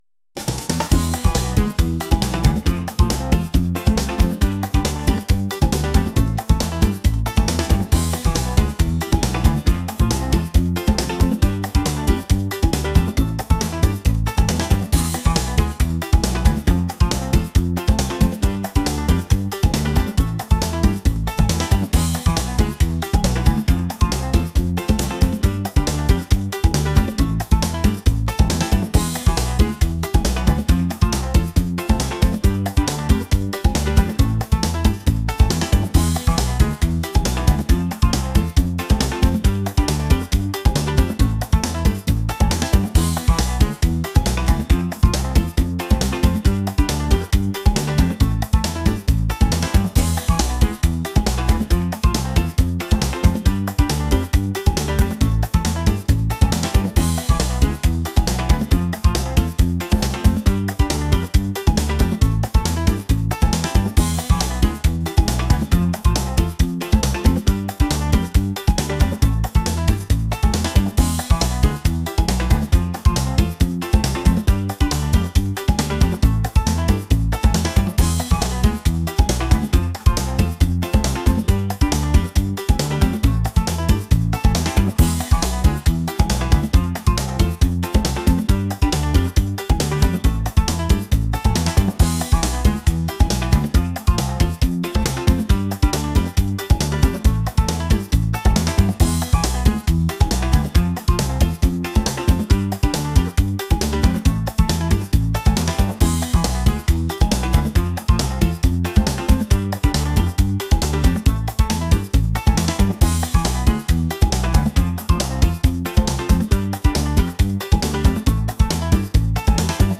rhythmic | pop | energetic